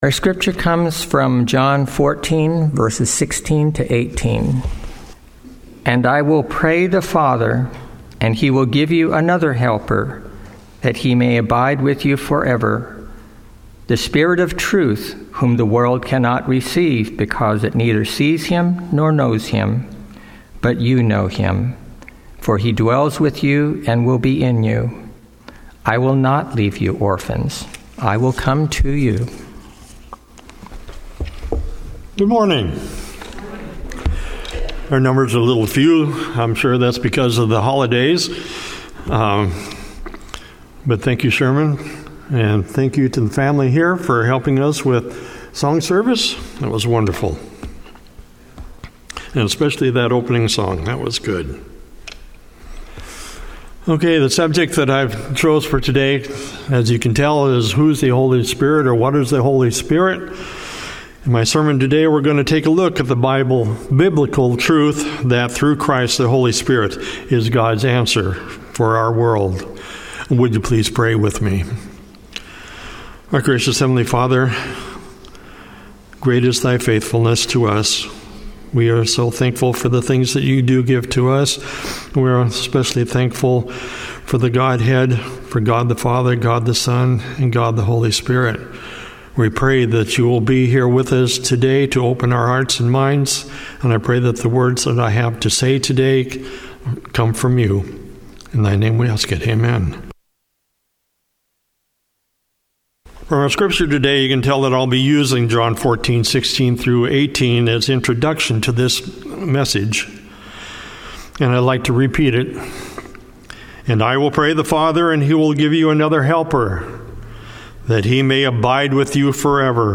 Sermons and Talks 2025 Download Other files in this entry Log in